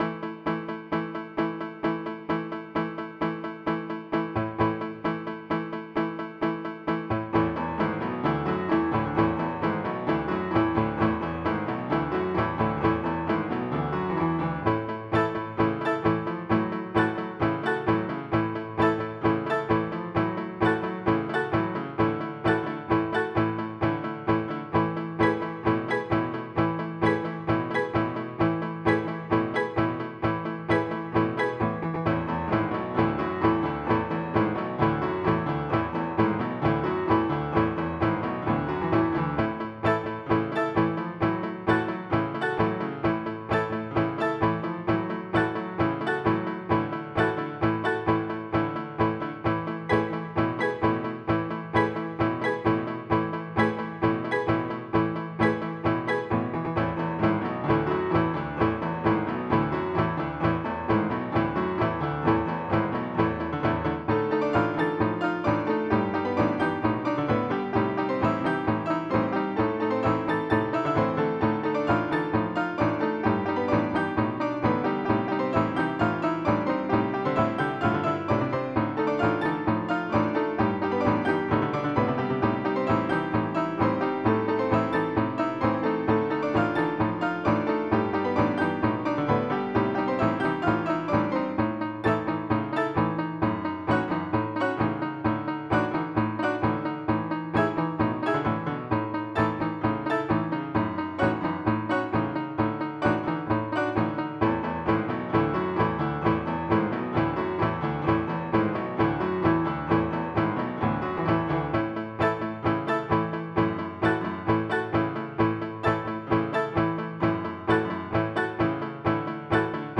SOFT.ROCK
MIDI Music File